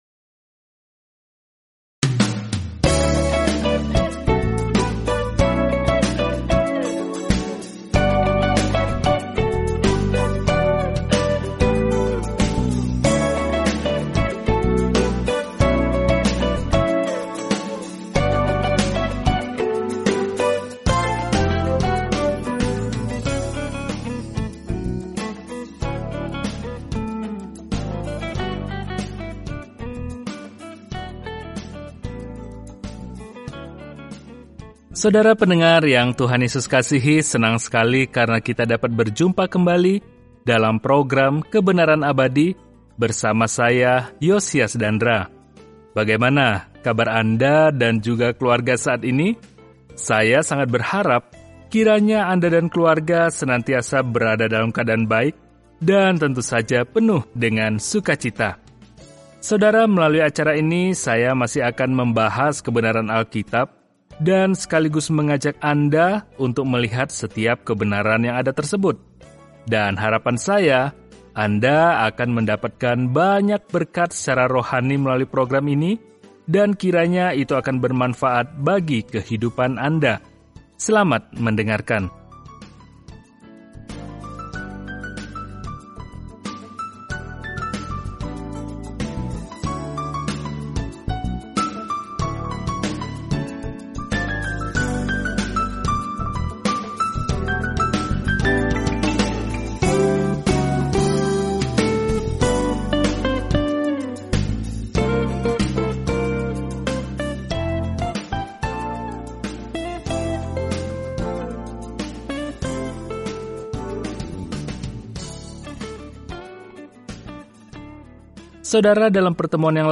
Firman Tuhan, Alkitab Maleakhi 3:17-18 Maleakhi 4 Hari 13 Mulai Rencana ini Hari 15 Tentang Rencana ini Maleakhi mengingatkan Israel yang terputus bahwa dia memiliki pesan dari Tuhan sebelum mereka mengalami keheningan yang lama – yang akan berakhir ketika Yesus Kristus memasuki panggung. Jelajahi Maleakhi setiap hari sambil mendengarkan pelajaran audio dan membaca ayat-ayat tertentu dari firman Tuhan.